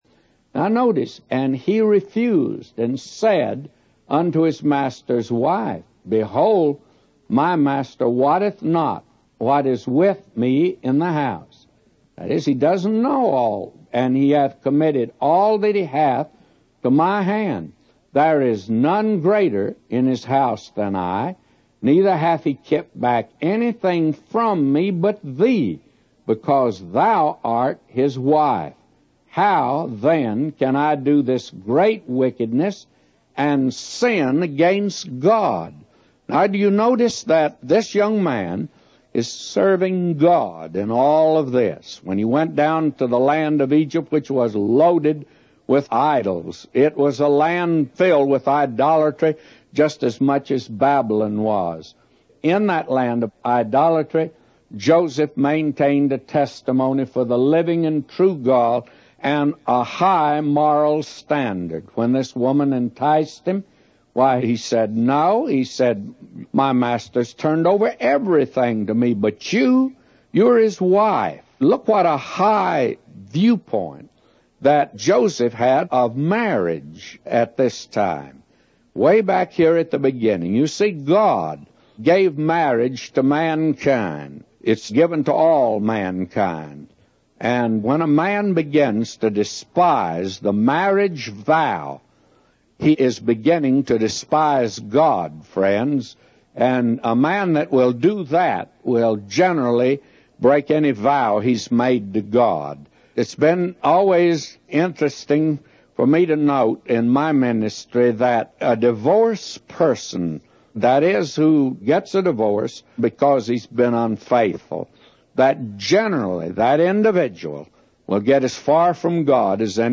A Commentary By J Vernon MCgee For Genesis 39:8-999